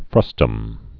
(frŭstəm)